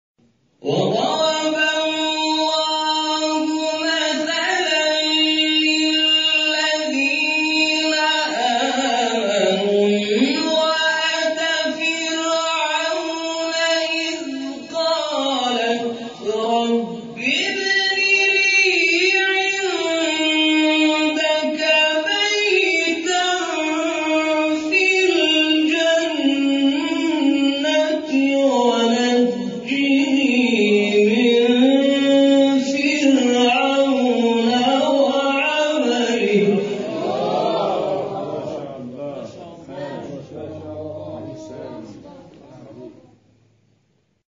گروه شبکه اجتماعی: فرازهای صوتی از تلاوت قاریان بنام و ممتاز کشور را می‌شنوید.
اجرا شده در مقام نهاوند